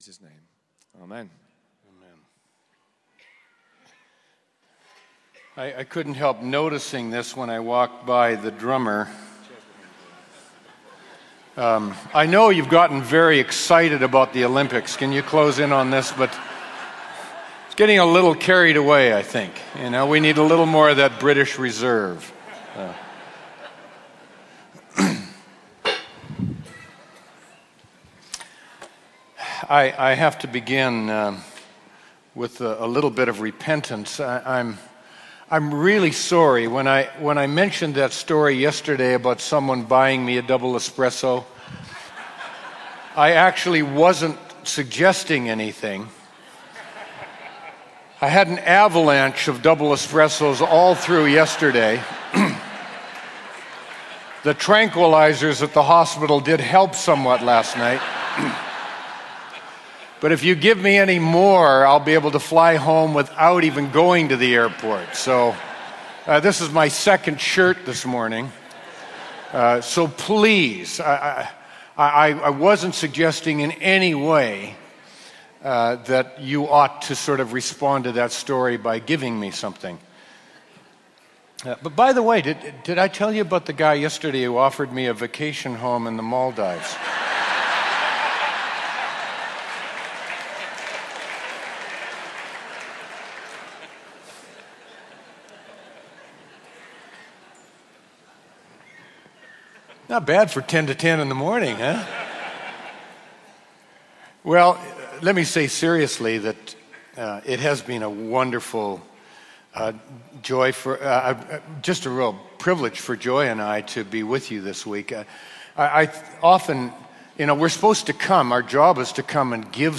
Teaching from New Wine Christian Conference – for all to share.
Talk